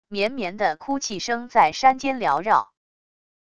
绵绵的哭泣声在山间缭绕wav音频